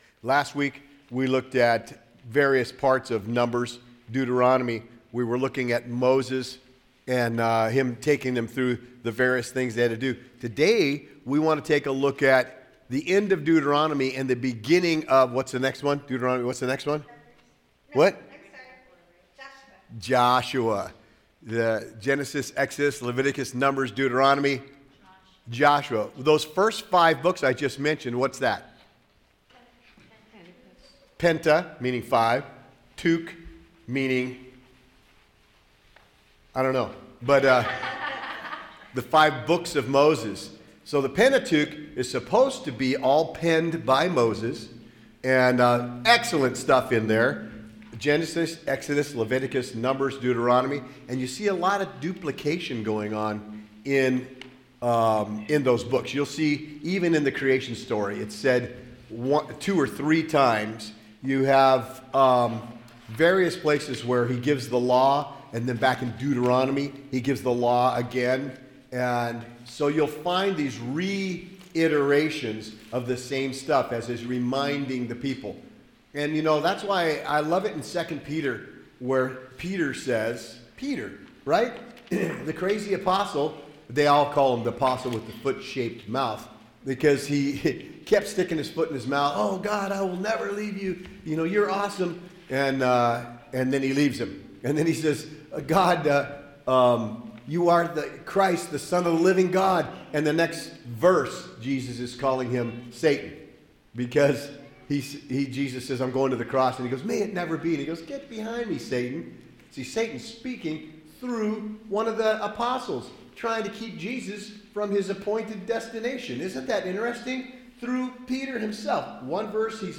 PREPARING TO ENTER THE PROMISED LAND All Sermons